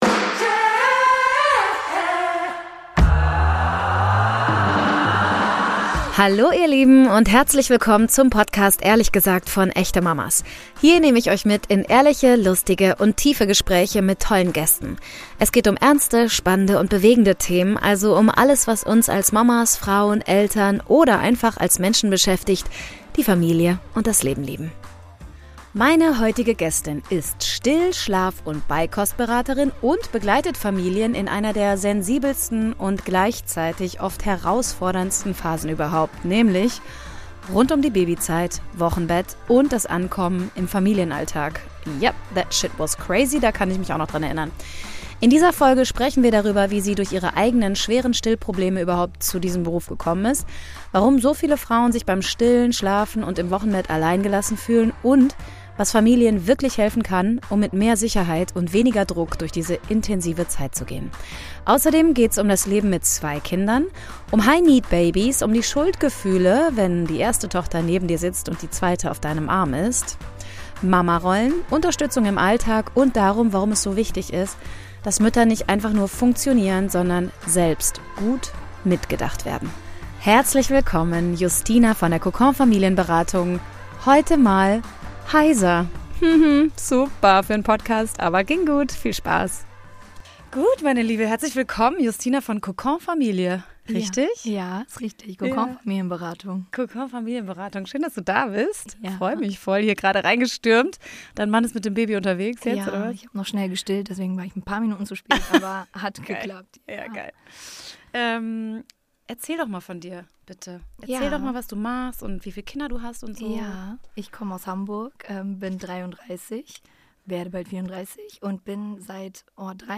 Noch heute zittert der Zweifach-Mama die Stimme, wenn sie von den dramatischen Stunden im Krankenhaus erzählt.